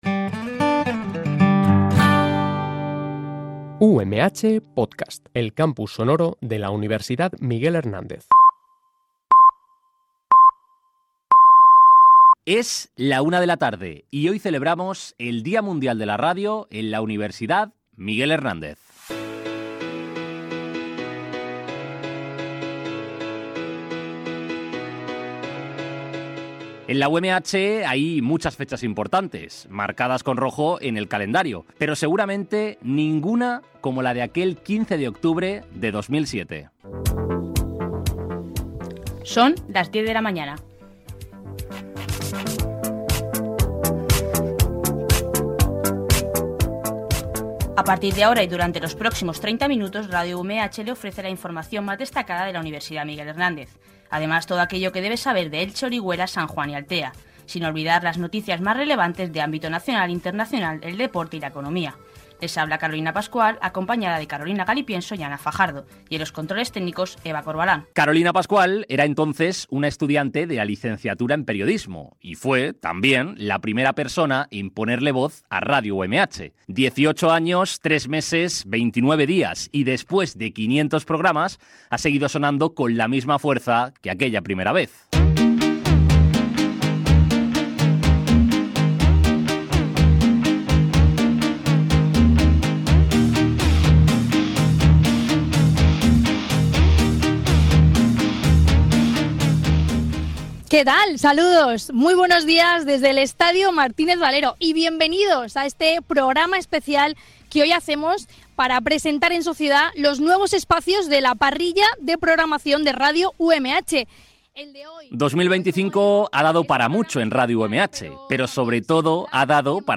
Este reportaje ha sido locutado y realizado